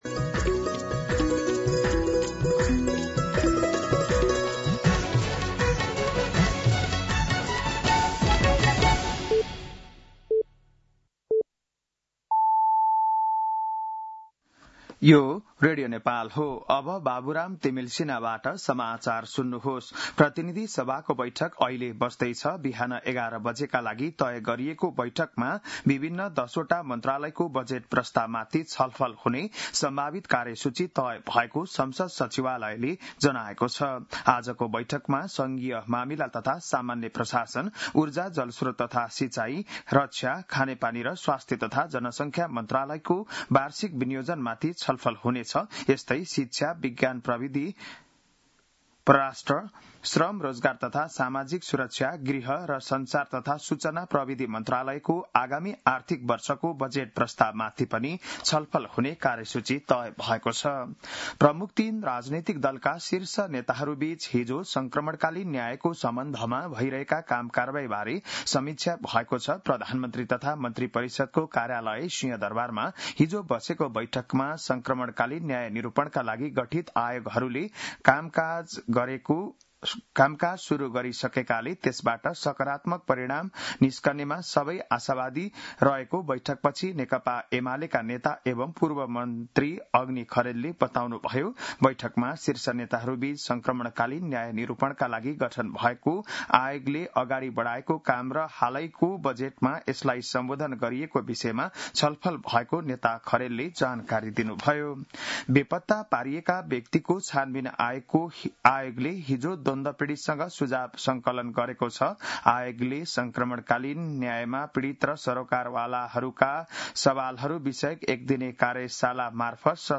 बिहान ११ बजेको नेपाली समाचार : ७ असार , २०८२
11-am-Nepali-News-3.mp3